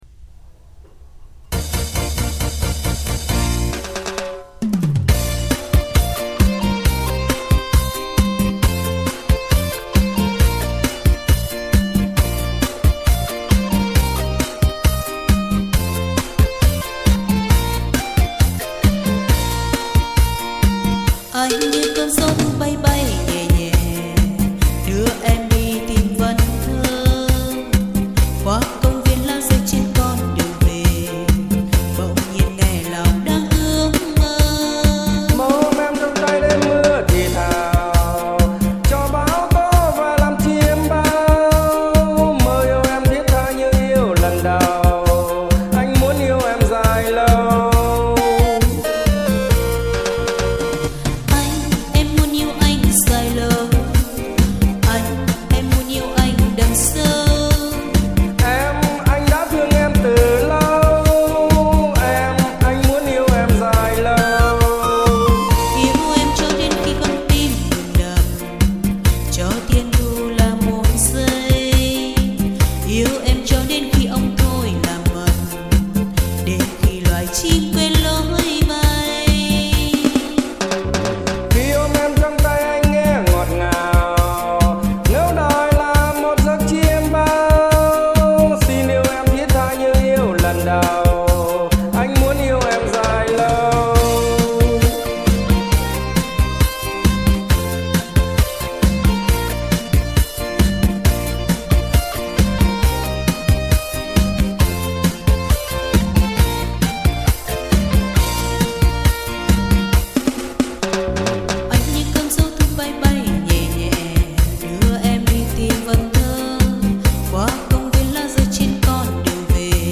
song ca